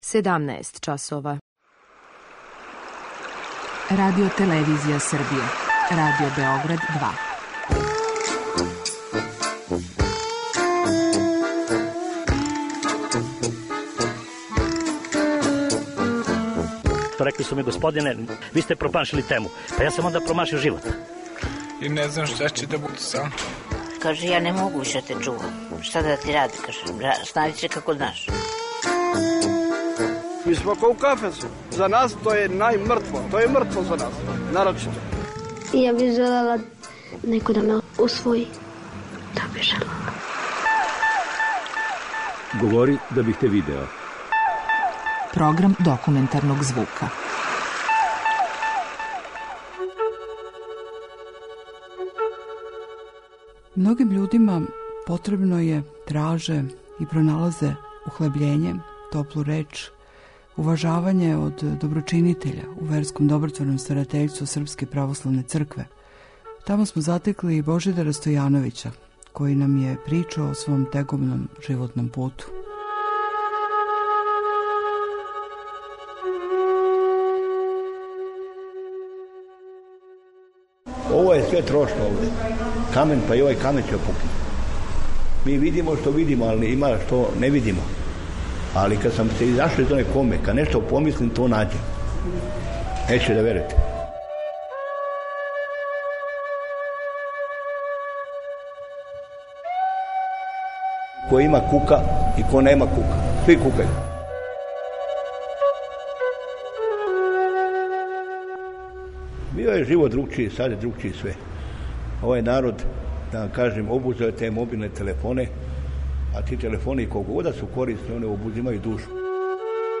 Документарни програм: И камен је трошан